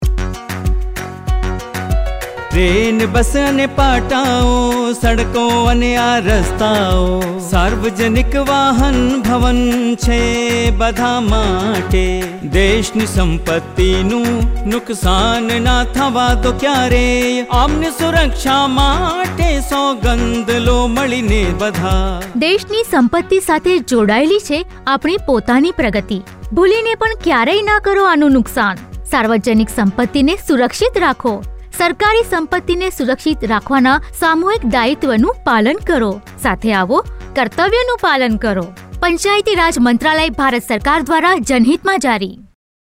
66 Fundamental Duty 9th Fundamental Duty Safeguard public property Radio Jingle Gujrati